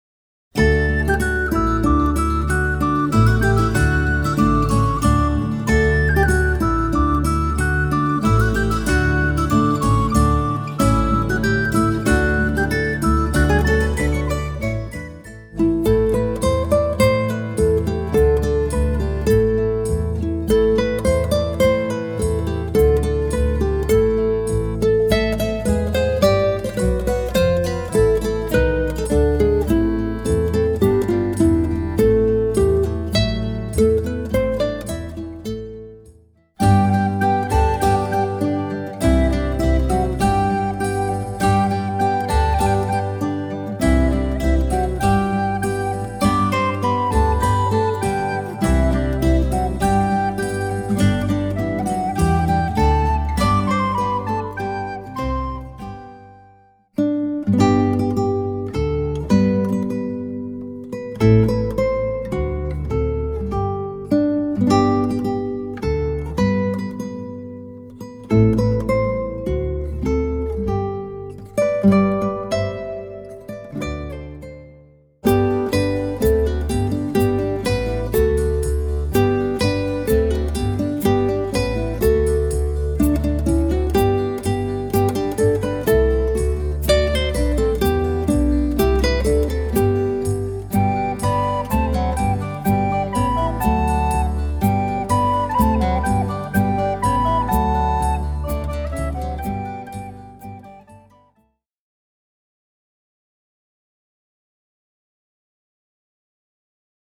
Holiday Music